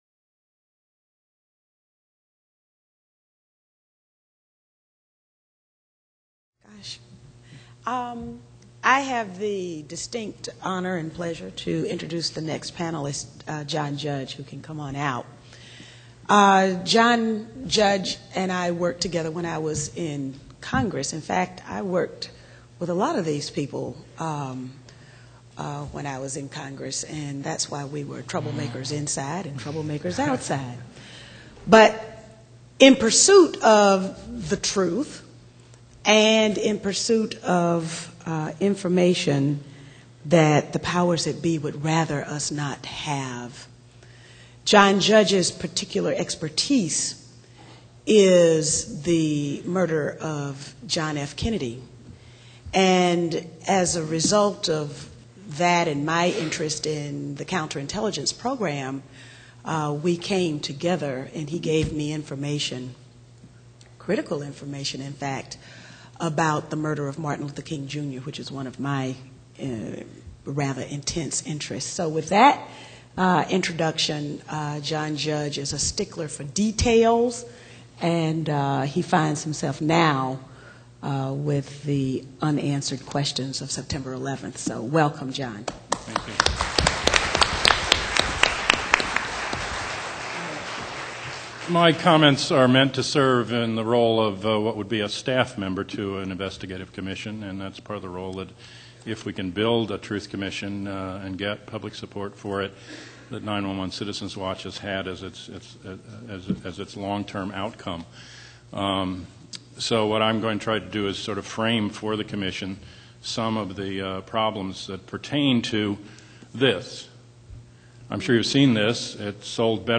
Introduction by Cynthia McKinney: